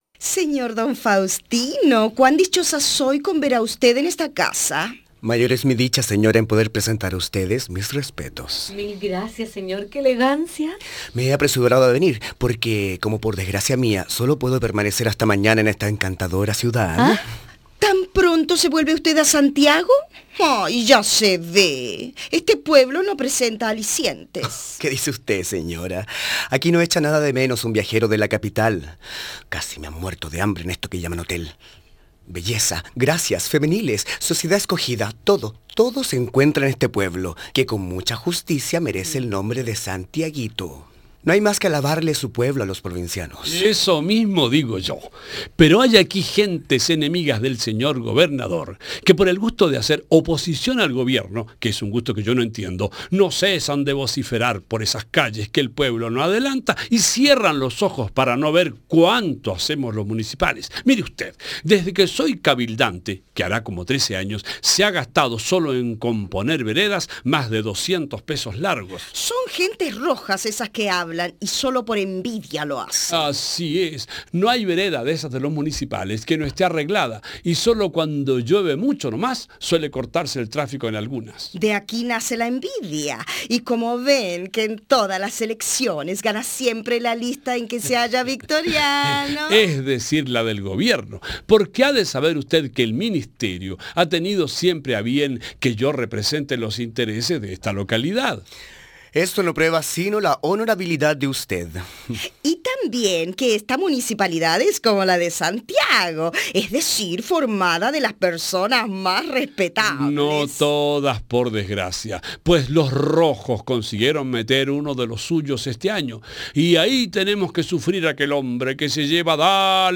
Como en Santiago – Lecturas dramatizadas